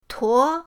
tuo2.mp3